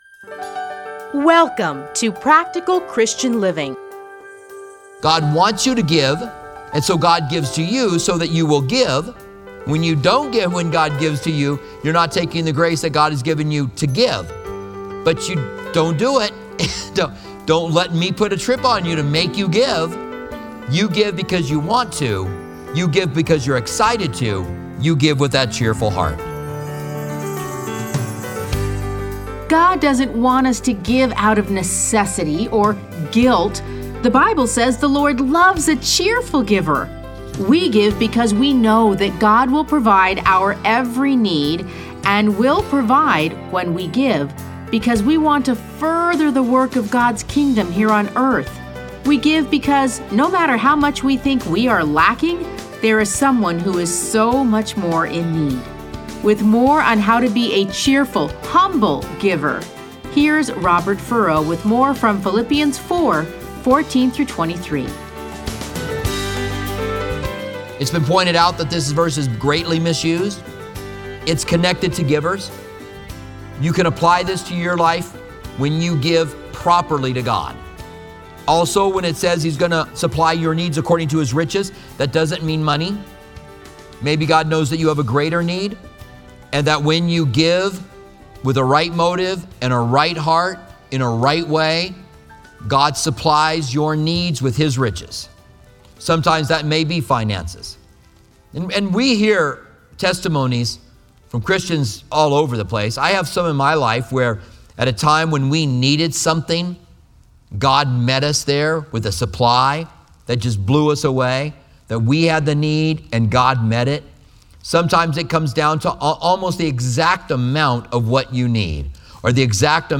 Listen to a teaching from A Study in Philippians 4:14-23.